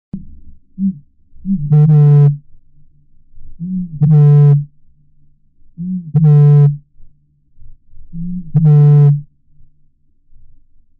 大麻鳽叫声低沉似牛叫